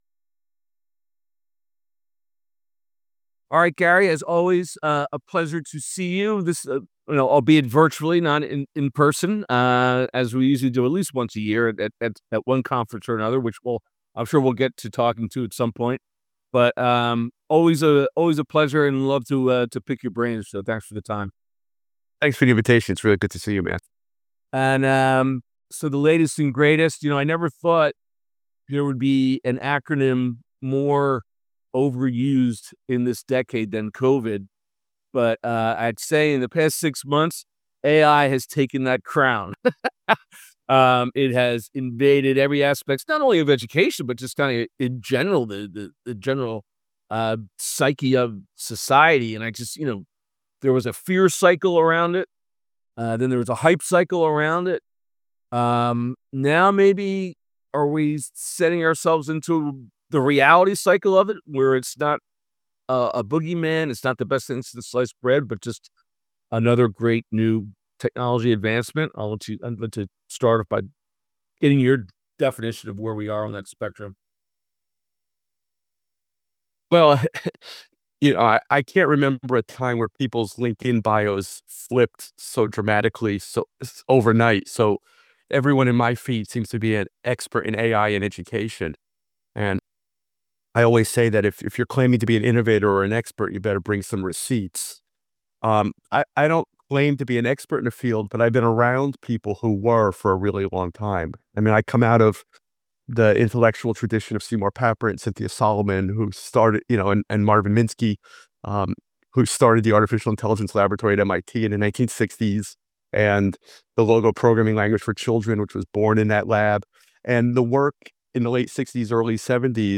Interview on AI in Education